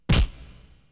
EFCPUNCH.WAV